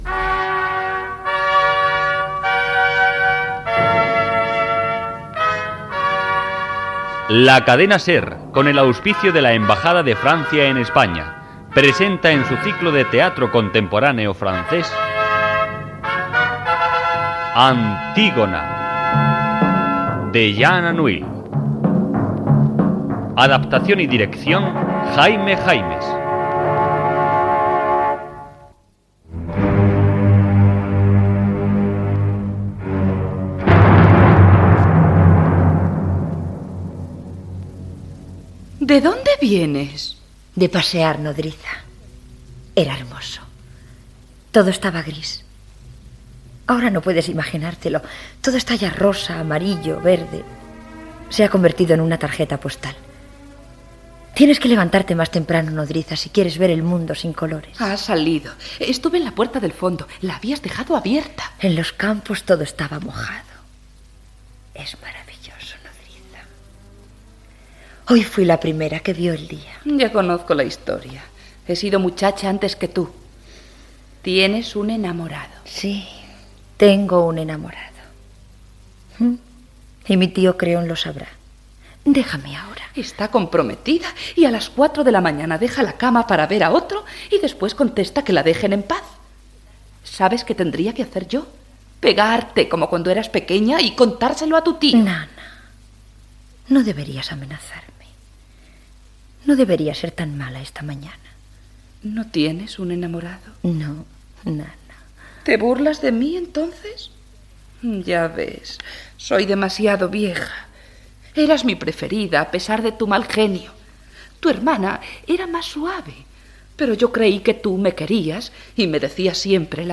Adaptació radiofònica de l'obra "Antígona" de Jean Anouilh. Careta del programa i primera escena de l'obra
Ficció